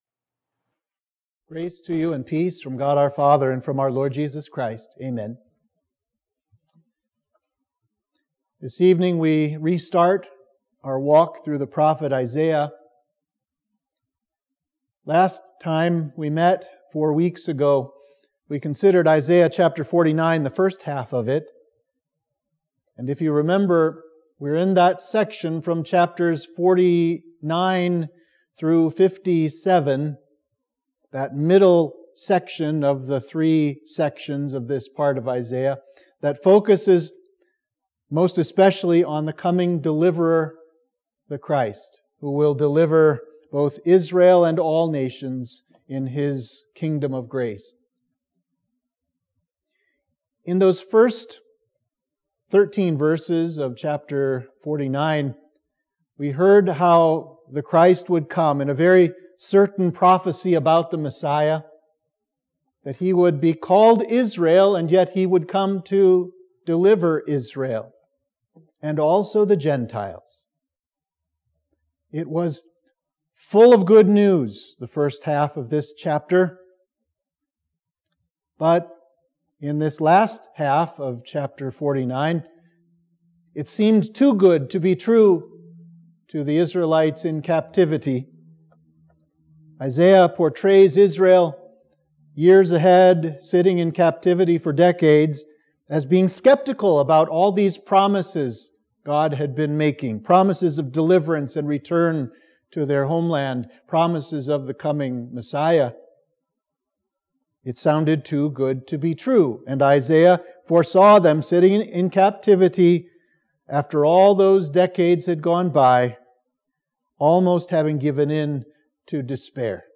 Sermon for Midweek of Trinity 7